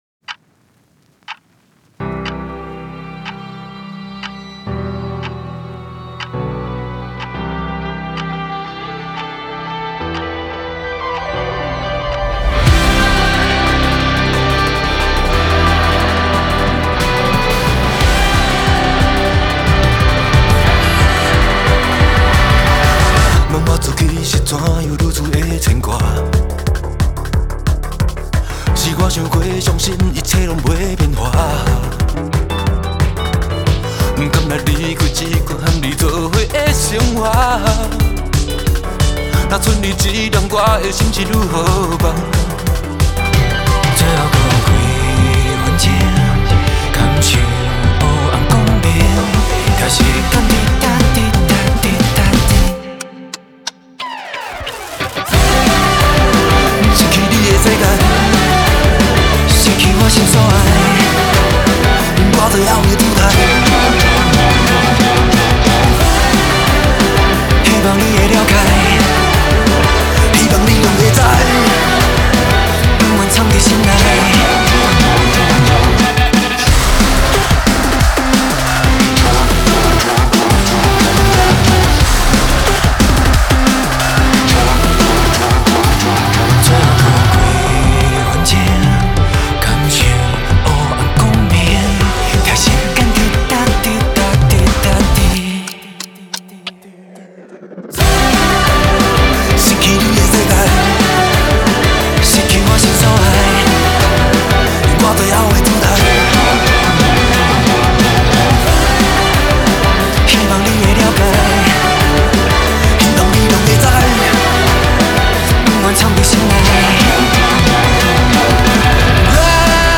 Ps：在线试听为压缩音质节选，体验无损音质请下载完整版
吉他 Guitar
贝斯 Bass
合成器 Synth
鼓组 Drums
和声演唱 Backing Vocal